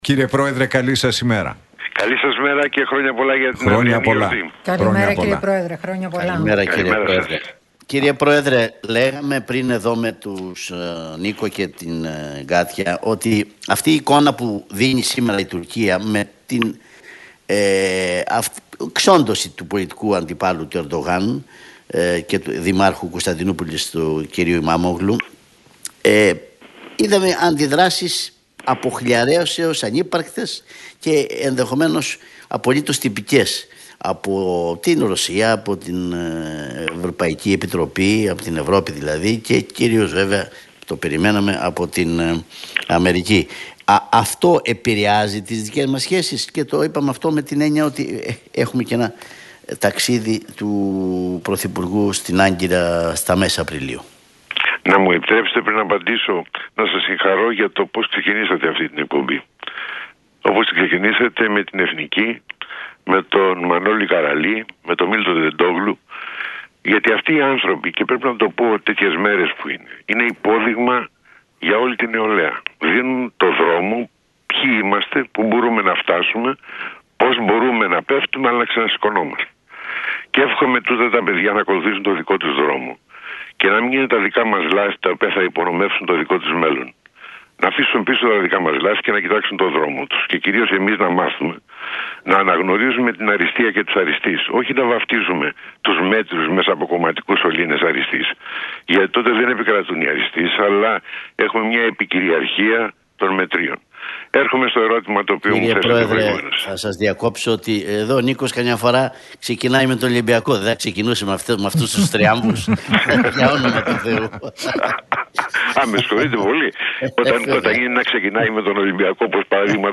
Για τις εξελίξεις στην Τουρκία, τις ήπιες αντιδράσεις από Ε.Ε. και ΗΠΑ και τις ευρύτερες γεωπολιτικές εξελίξεις μίλησε ο πρώην Πρόεδρος της Δημοκρατίας,